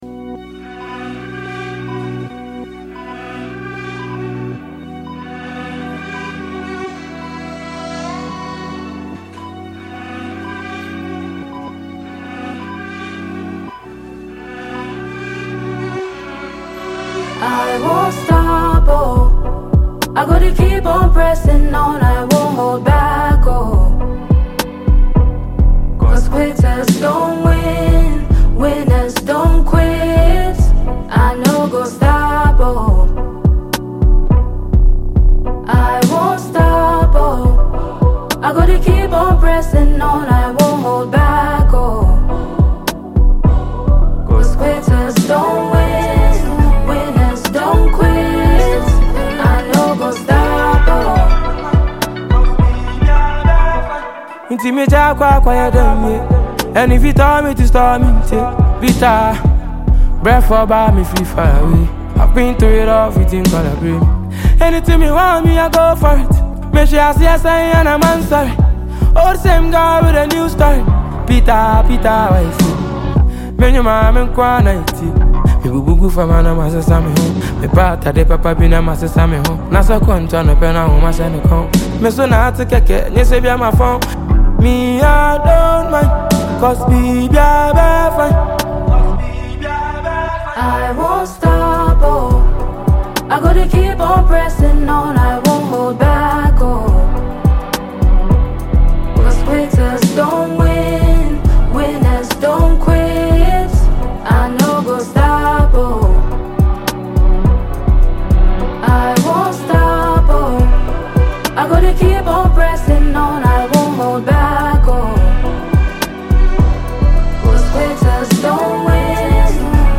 a renowned Afrobeat singer